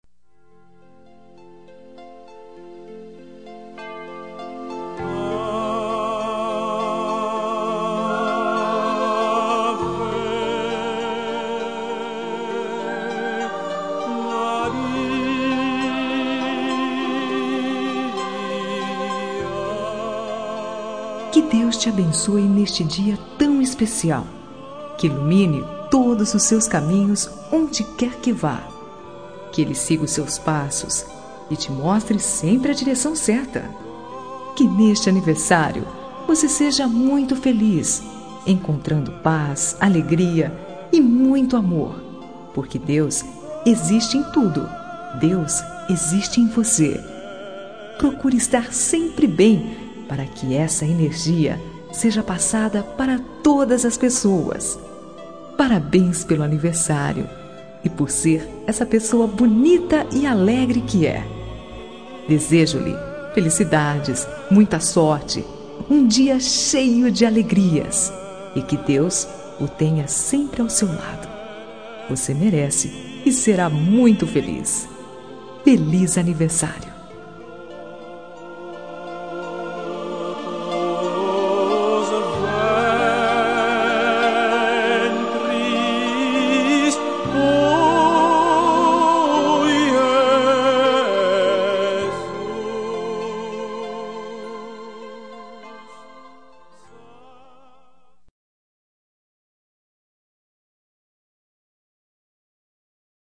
Aniversário Religioso – Voz Feminina – Cód: 34899 – Linda.
34899-religiosa-fem.m4a